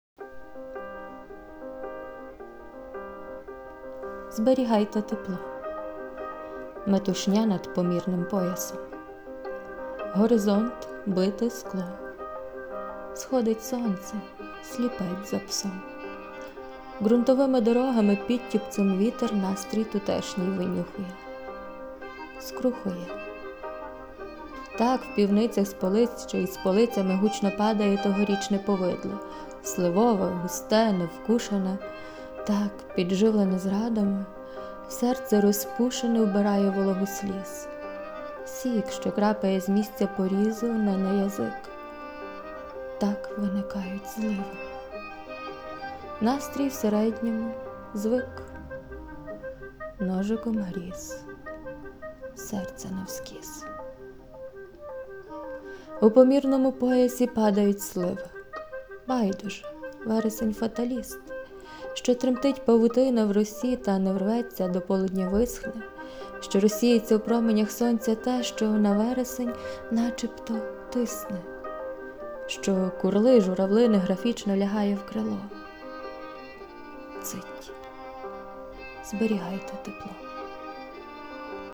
СТИЛЬОВІ ЖАНРИ: Ліричний
ВИД ТВОРУ: Вірш